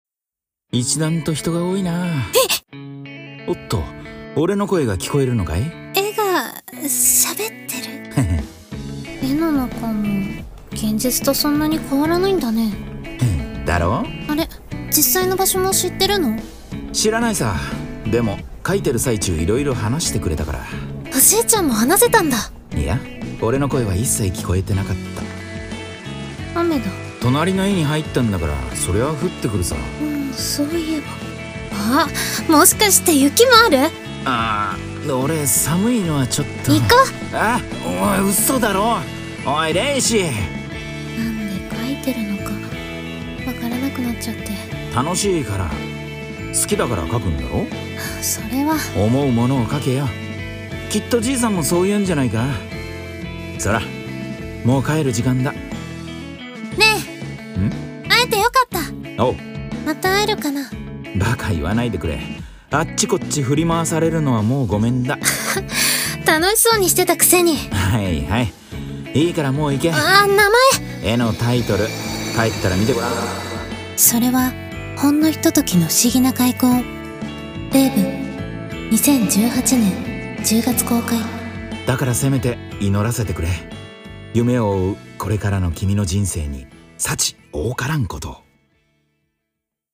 【声劇】レーヴ